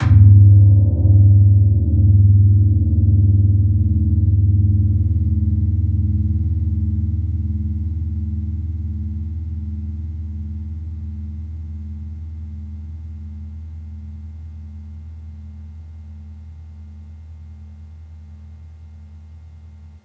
Gong-G#1-f.wav